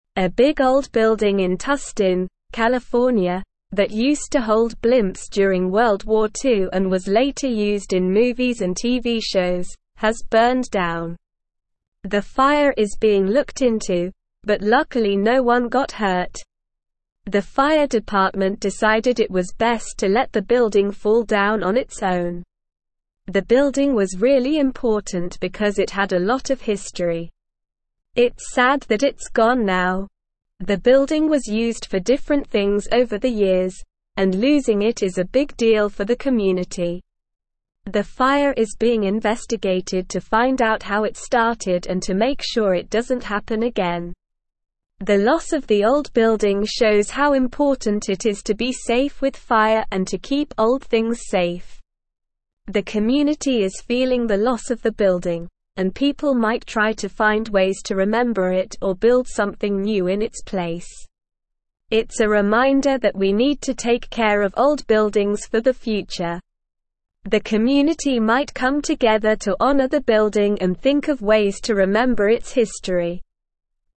Slow
English-Newsroom-Upper-Intermediate-SLOW-Reading-Historic-World-War-Two-Era-Blimp-Hangar-Engulfed-in-Flames.mp3